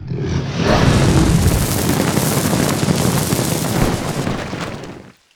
combat / dragons / fireblow.wav
fireblow.wav